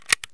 ammo_load3.ogg